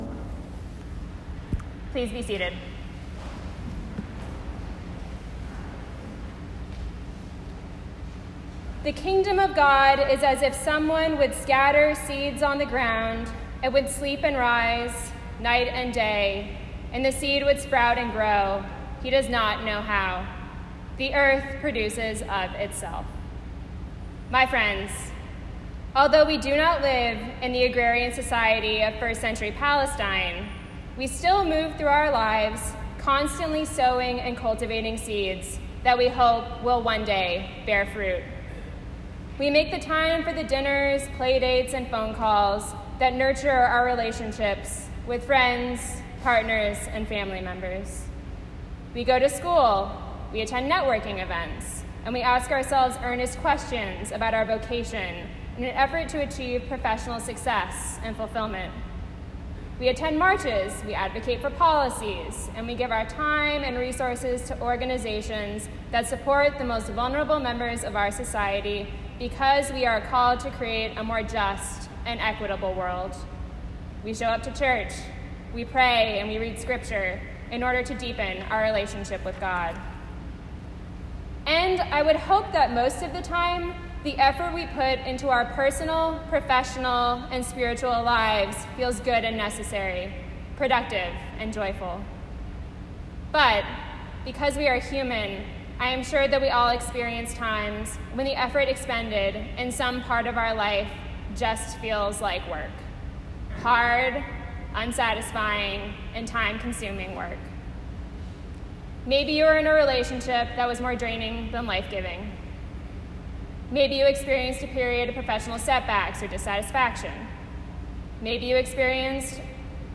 Audio Video: Sermon begins at 17:53.
sermon-6-17-18.m4a